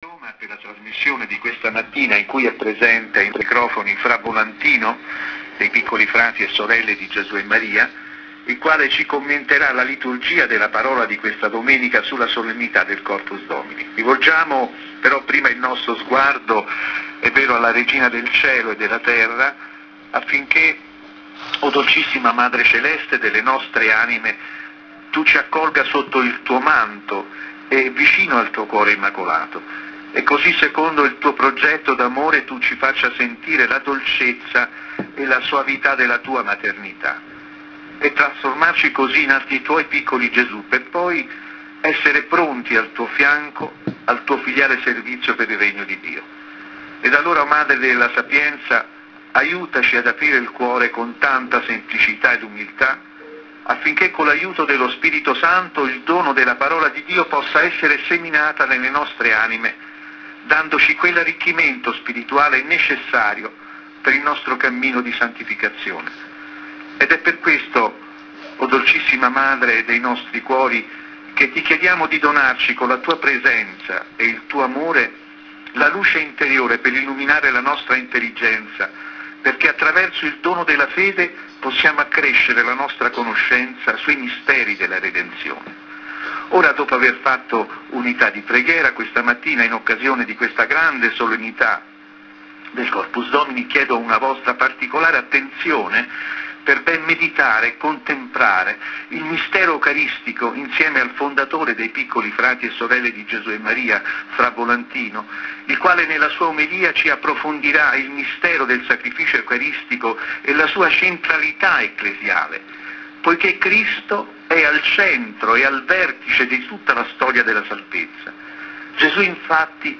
INTERVIEWS RADIOPHONIQUES DES PETITS V.V. ...